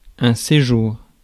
Ääntäminen
IPA: [se.ʒuʁ]